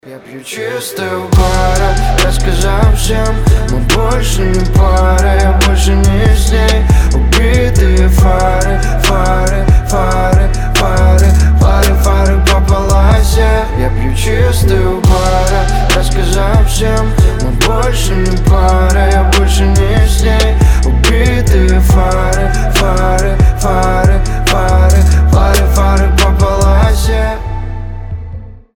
• Качество: 320, Stereo
лирика
грустные